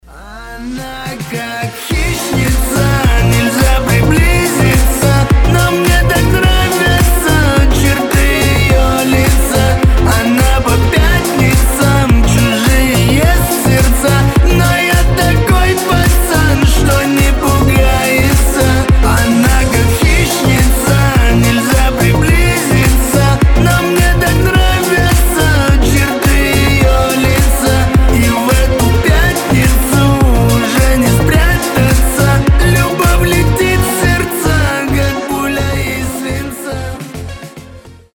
мужской вокал